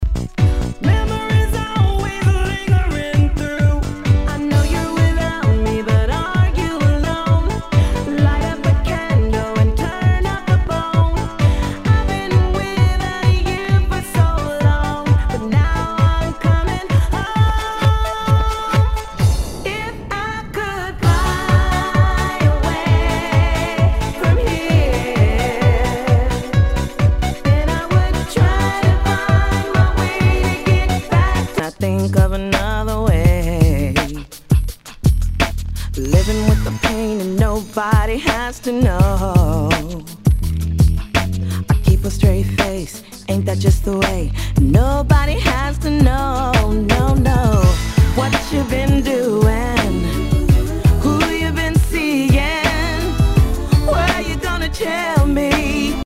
HOUSE/TECHNO/ELECTRO
ナイス！ユーロ・ヴォーカル・ハウス / R&B！
全体にチリノイズが入ります